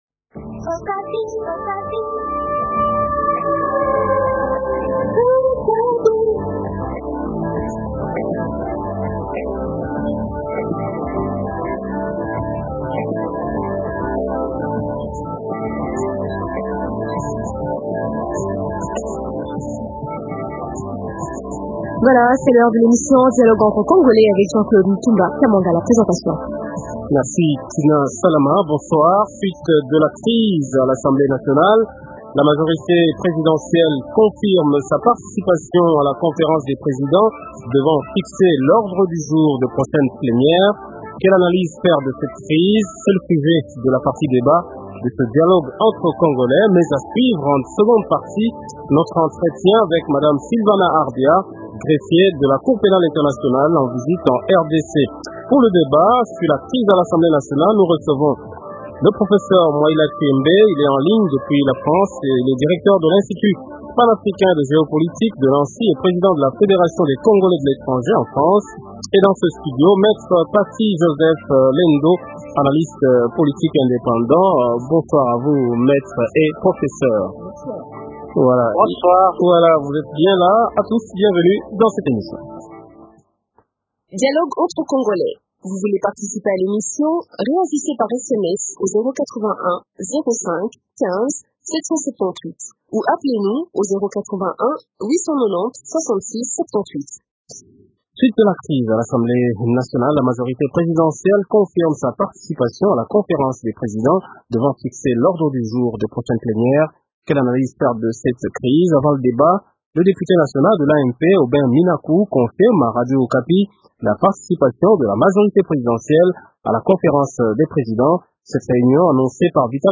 Quelle Analyse faire de cette crise ? C’est le sujet de la partie débat de dialogue entre congolais de ce soir , mais à suivre aussi en seconde partie , l’interview que nous a accordée Madame Sylvana Arbia , greffier de la CPI en visite en RDC.